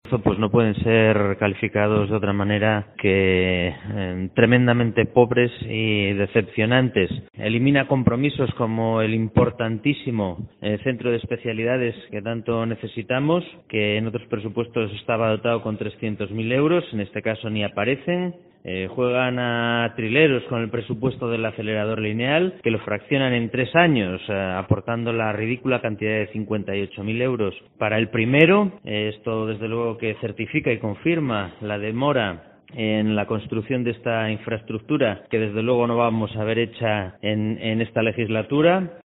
Escucha aquí las palabras del procurador berciano Javier Campos